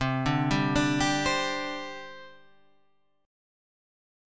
C Suspended 2nd